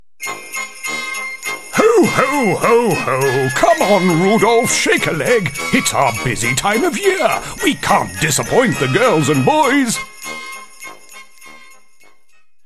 Character and Cartoon voices
Jolly Santa character- lots of ho ho